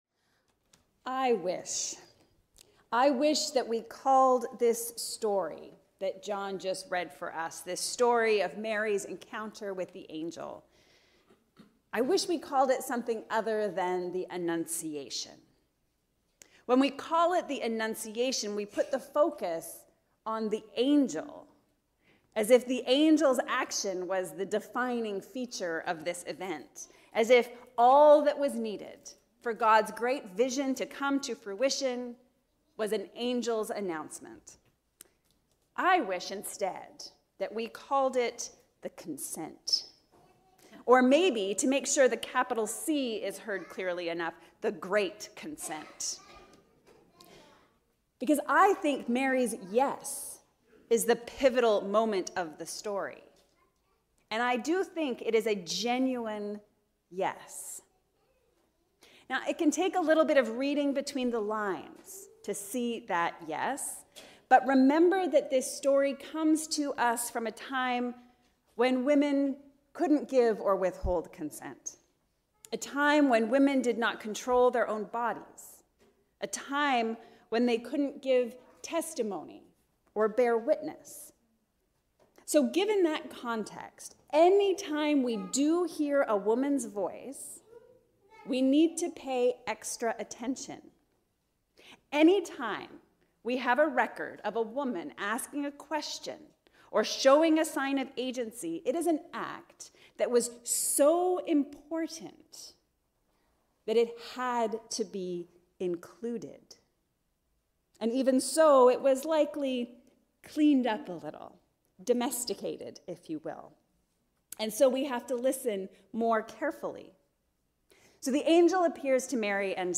Mary’s “Yes”. A Sermon for the 4th Sunday of Advent.